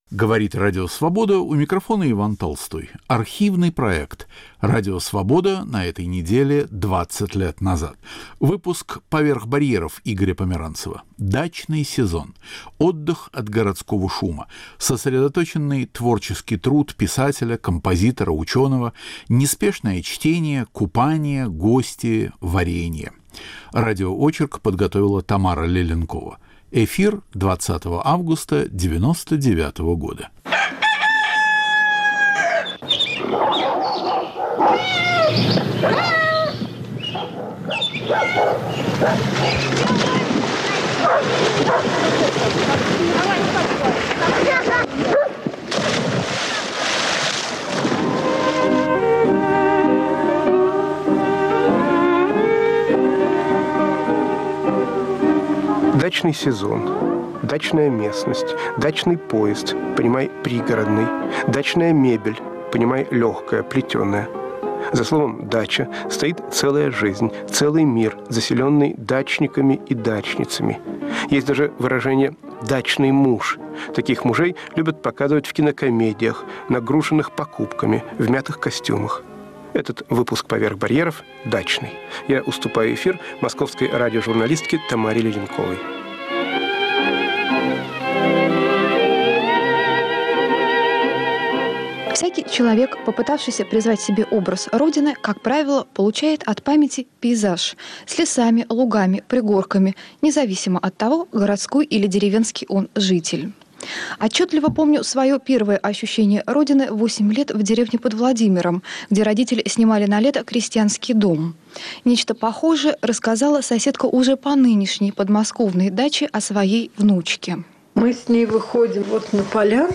Архивный проект.